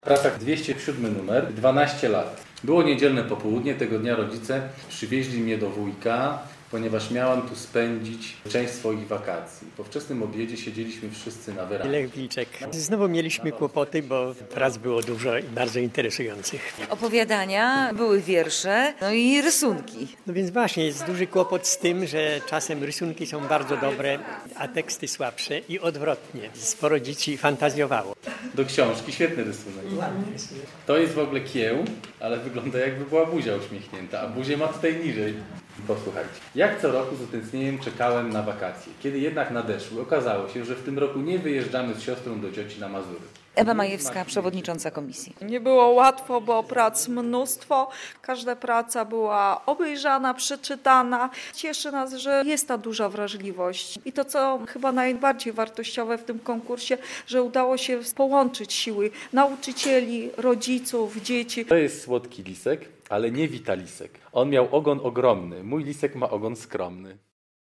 Obrady jury konkursu literacko-plastycznego Polskiego Radia Białystok poświęconego pamięci prof. Simony Kossak - relacja